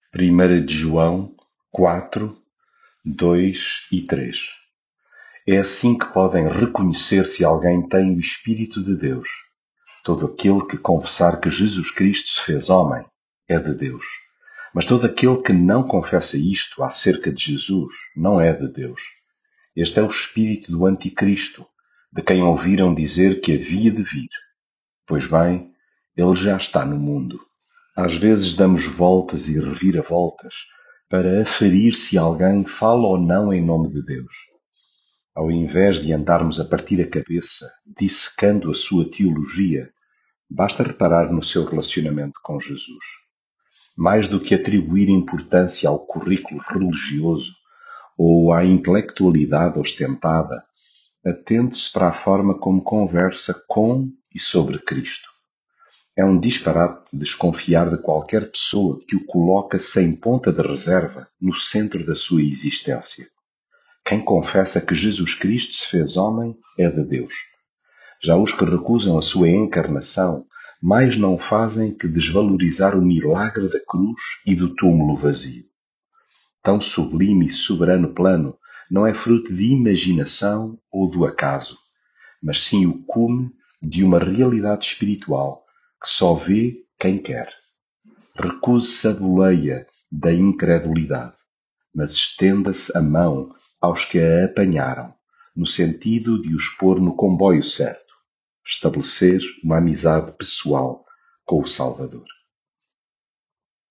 Devocional Coríntios
leitura bíblica